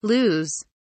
lose kelimesinin anlamı, resimli anlatımı ve sesli okunuşu